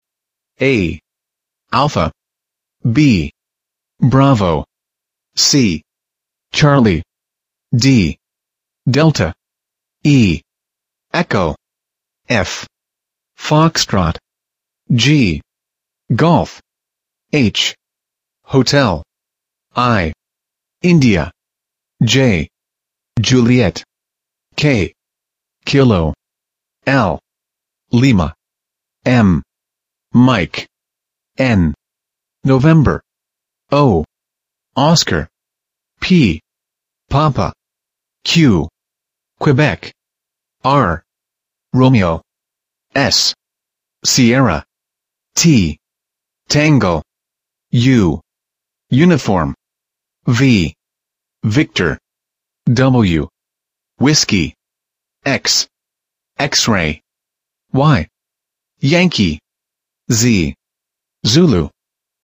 Pronúncia
A-Z-Phonetic-Alphabet.mp3